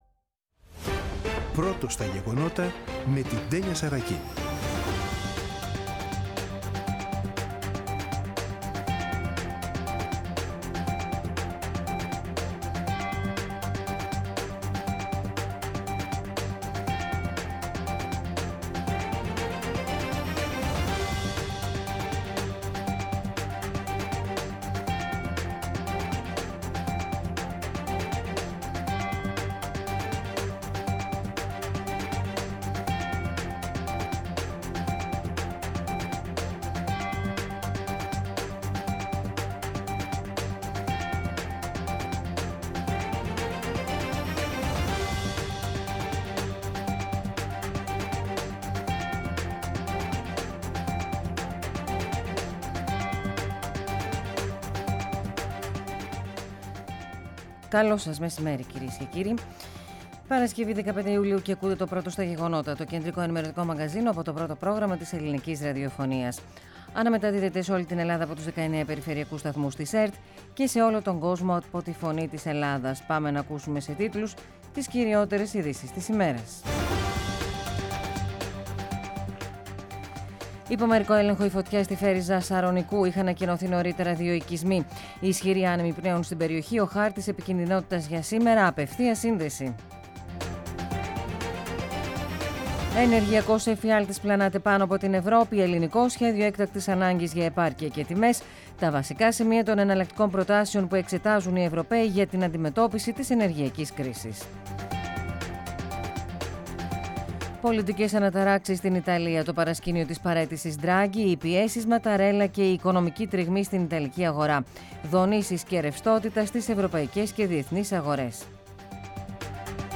“Πρώτο στα γεγονότα”. Το κεντρικό ενημερωτικό μαγκαζίνο του Α΄ Προγράμματος , από Δευτέρα έως Παρασκευή στις 14.00. Με το μεγαλύτερο δίκτυο ανταποκριτών σε όλη τη χώρα, αναλυτικά ρεπορτάζ και συνεντεύξεις επικαιρότητας.